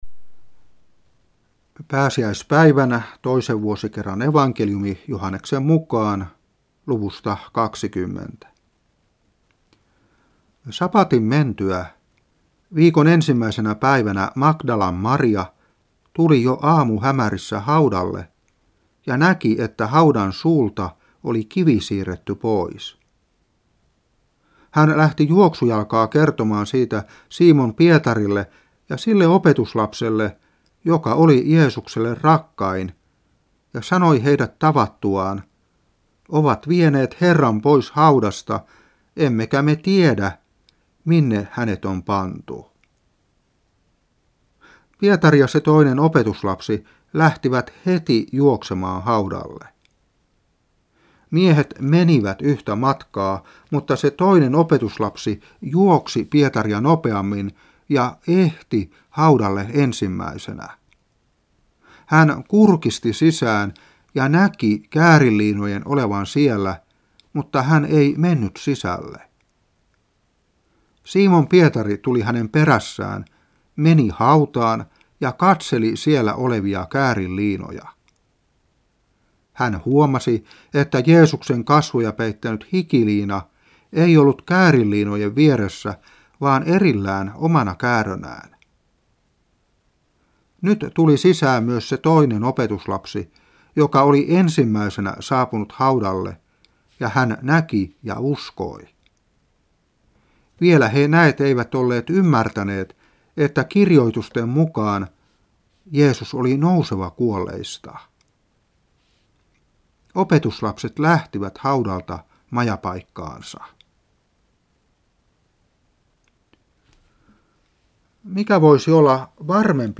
Saarna 2017-4. Joh.20:1-10.